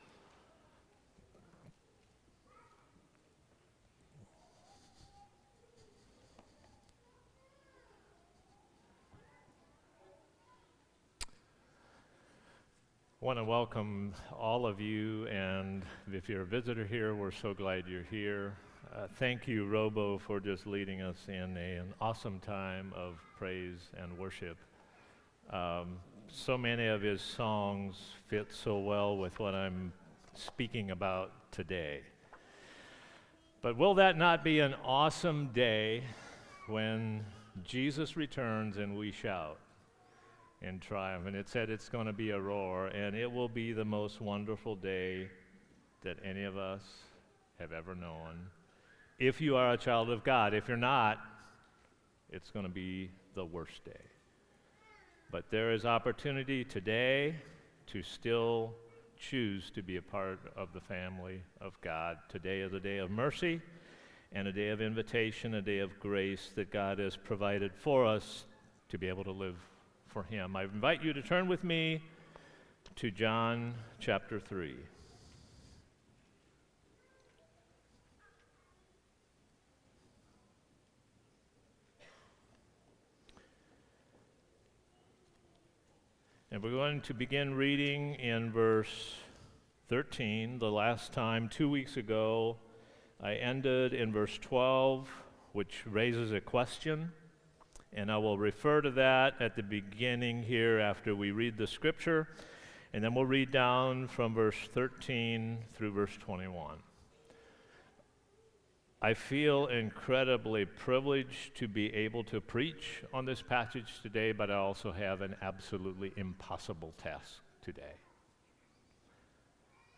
Sermons | Christian Life Mennonite
Guest Speaker